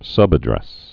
(sŭbə-drĕs)